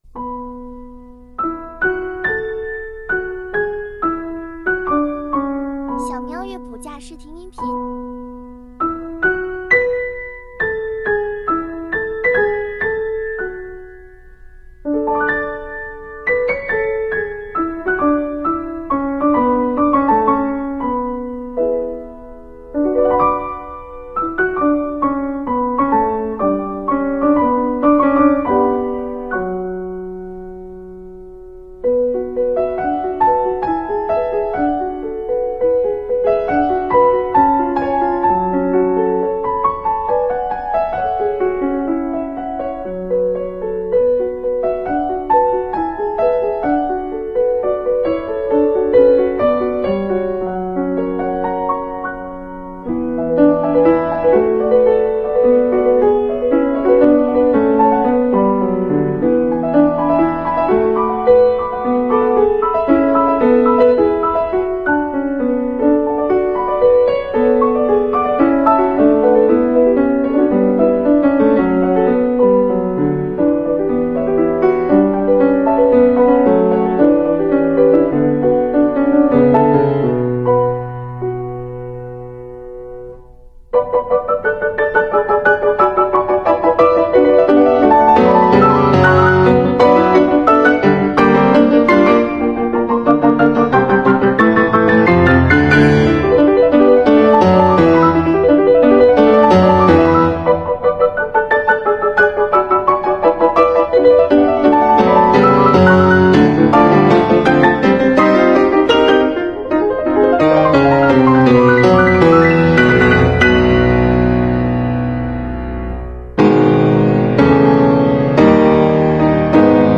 新疆风